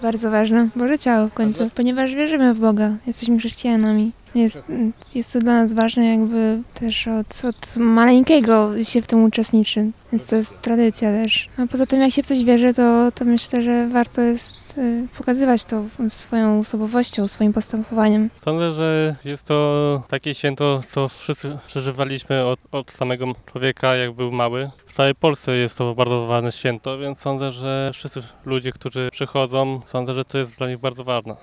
– Uroczystość Bożego Ciała to publiczna manifestacja wiary – mówią mieszkańcy.